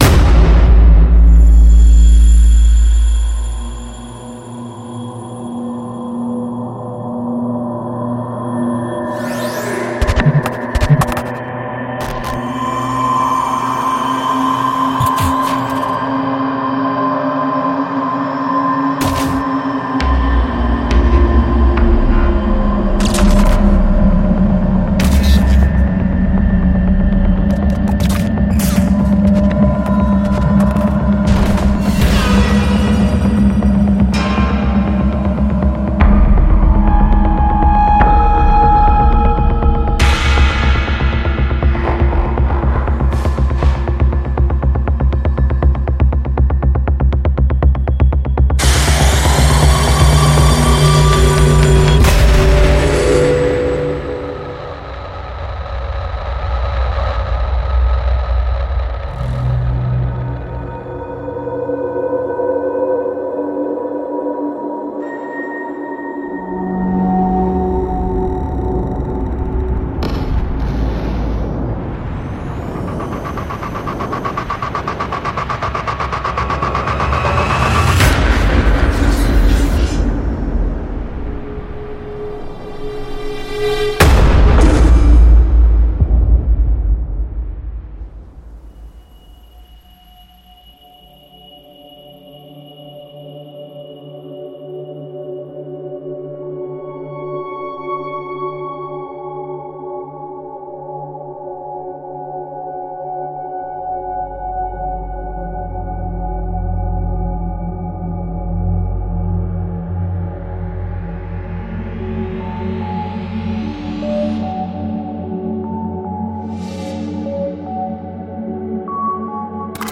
还使用了多种foley声源和现场录音，从简单的厨房工具到发现的金属打击乐和工业机械，确保了SFX原料的完美排列。
......::::::预览PRODUCT DEMO/PREViEW ::::::......